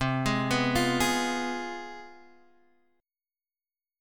Fdim/C chord